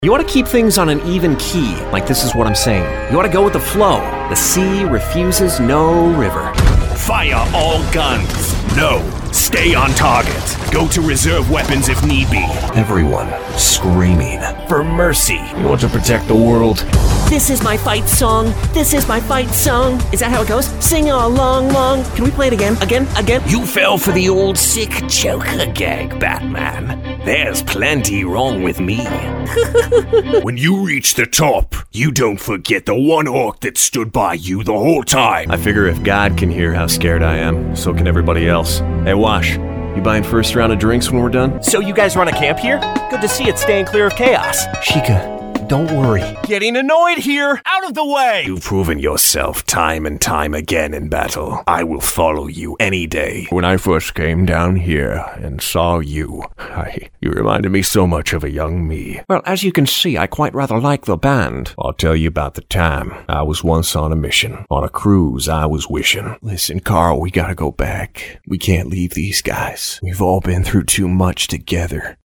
Young Adult, Adult
Has Own Studio
standard us | natural
GAMING 🎮